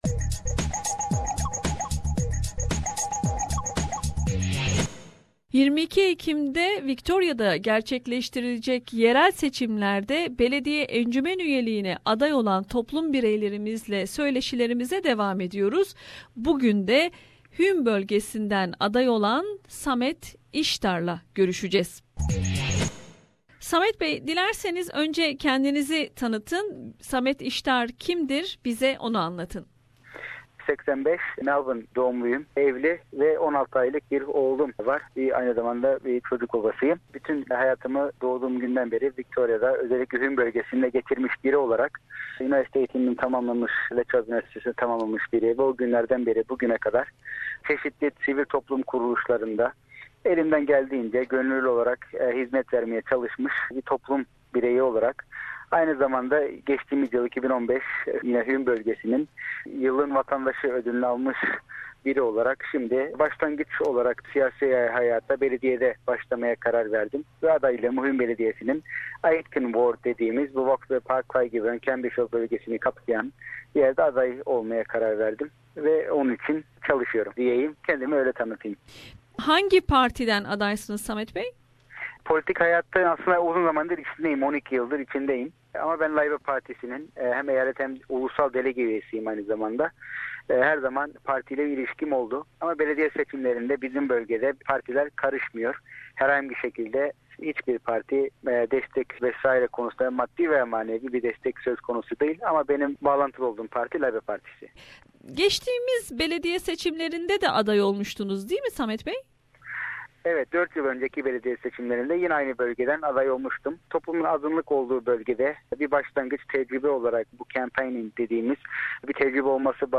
Victoria yerel seçimleri 22 Ekim Cumartesi günü yapılacak. SBS radyosu Türkçe programı olarak Avustralya Türk toplumunun yoğun olarak yaşadığı bölgelerden belediye encümen üyeliğine aday olan kişilerle söyleşiler yapmaktayız.